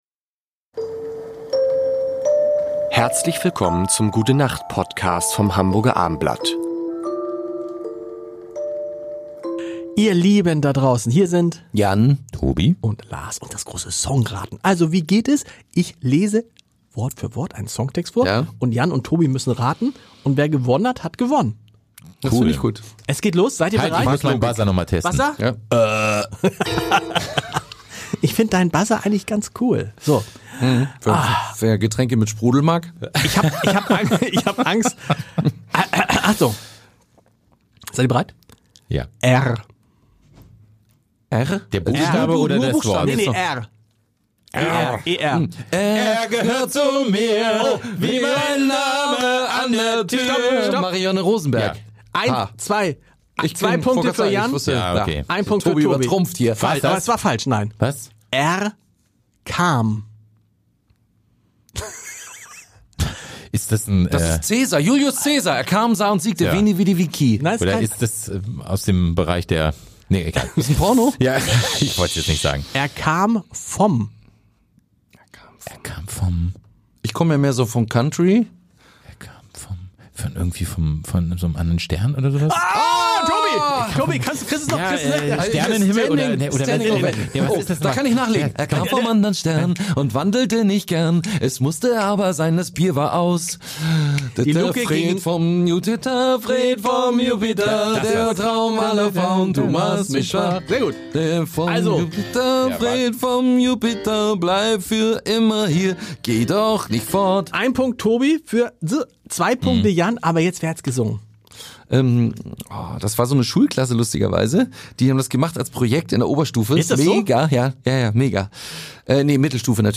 Jetzt jede Woche: Songtexte raten und singen